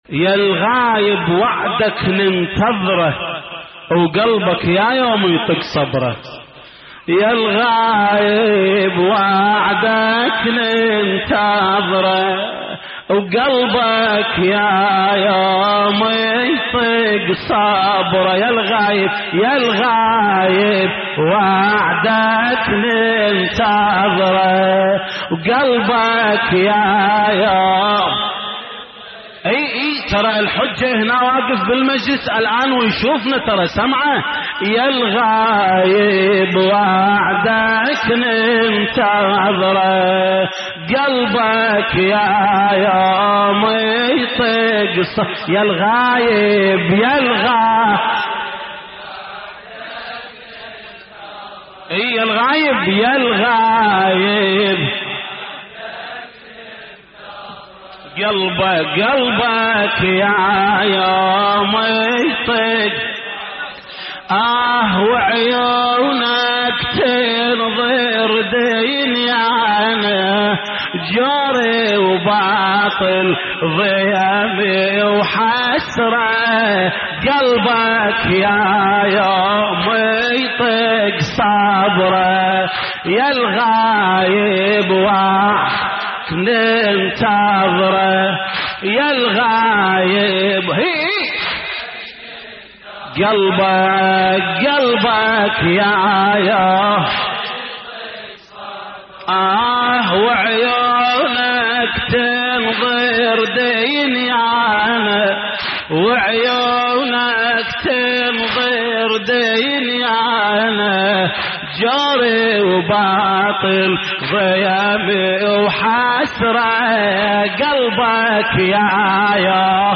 يالغايب وعدك ننتظره قلبك يا يوم يطق صبره / الرادود
اللطميات الحسينية